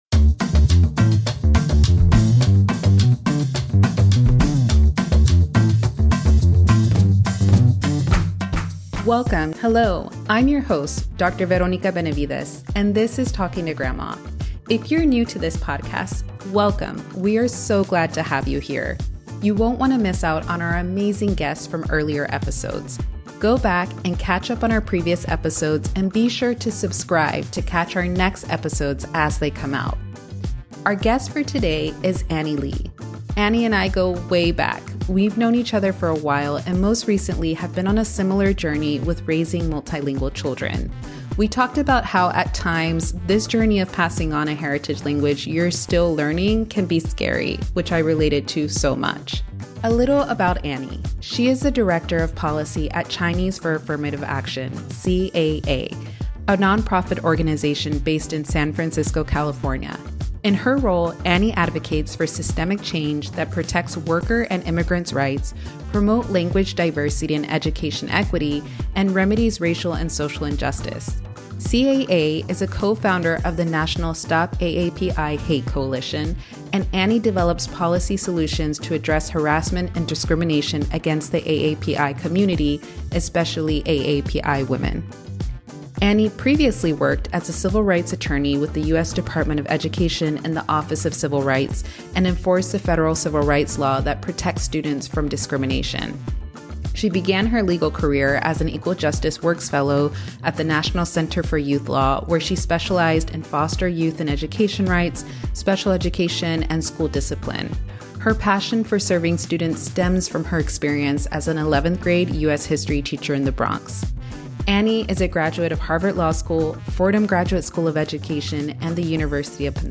If you have doubts and fears about your own bilingual parenting journey, join us in this conversation full of wisdom and reminders to give yourself grace!